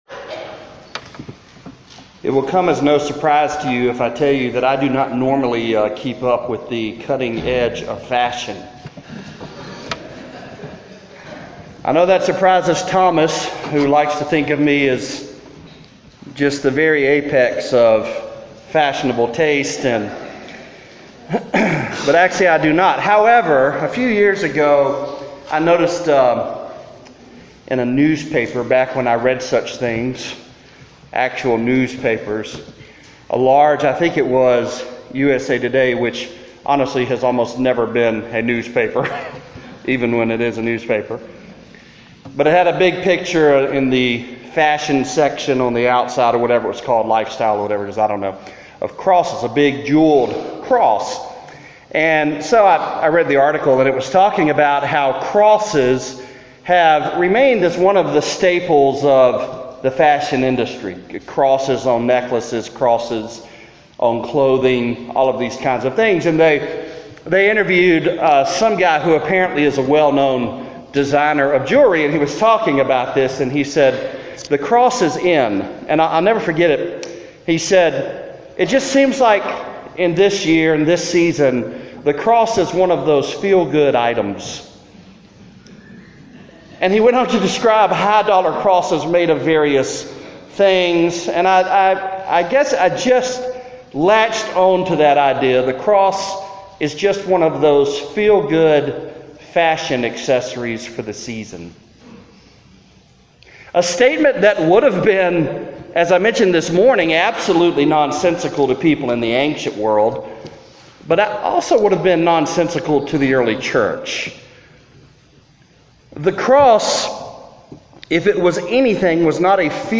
Ephesians 2:11-22 (Preached on March 4, 2018, at Central Baptist Church, North Little Rock, AR)